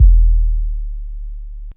HBA1 18 bass hit.wav